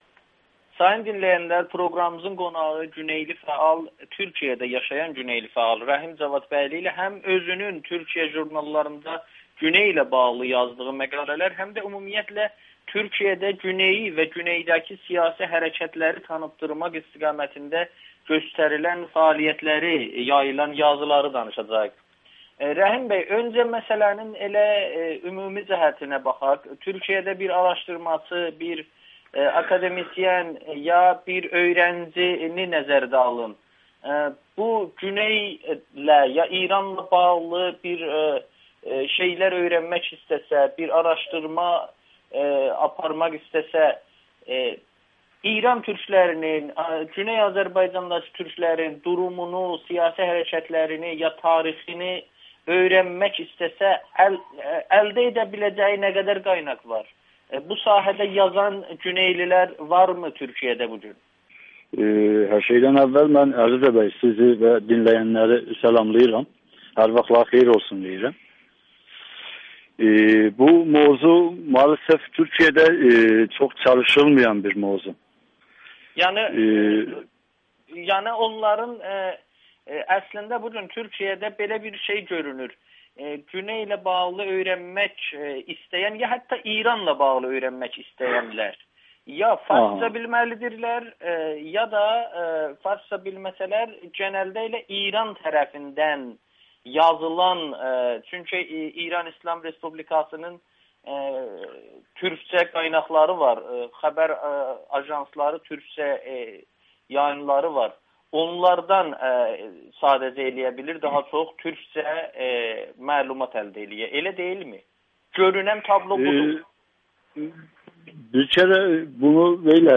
Türkiyədə İrandakı siyasi türklük üzərində tədqiqat azdır [Audio-Müsahibə]